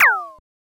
CD-ROOM/Assets/Audio/SFX/laser3.wav at main
laser3.wav